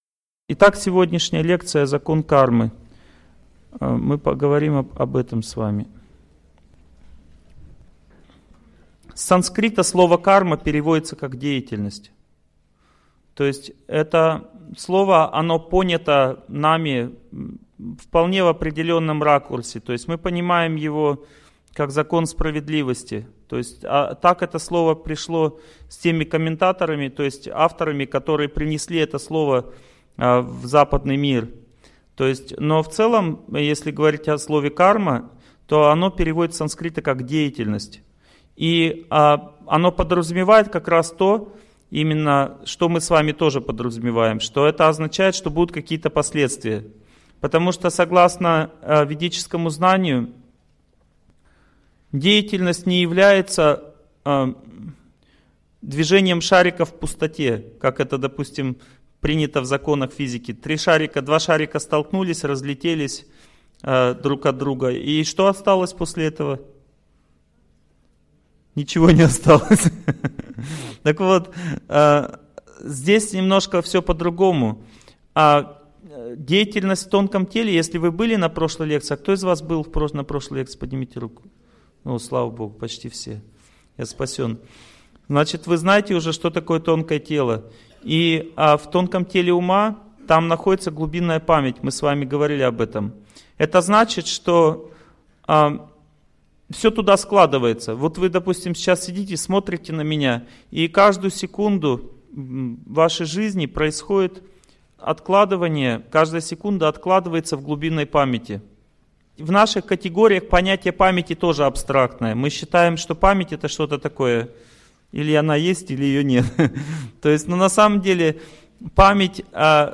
Аудиокнига Веды о законе кармы | Библиотека аудиокниг